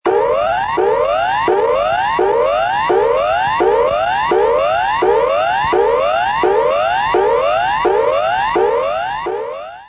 Sound Effects
Standard Emergency Warning Signal